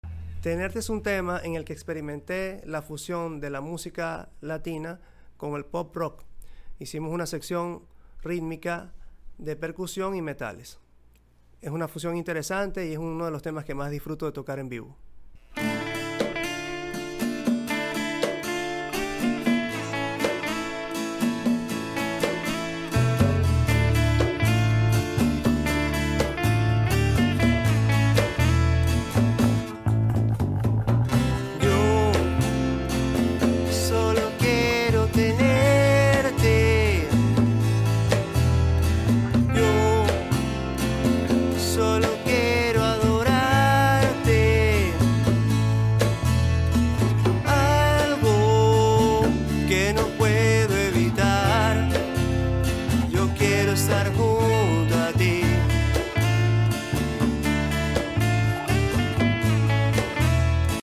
fusión de Pop Rock con música latina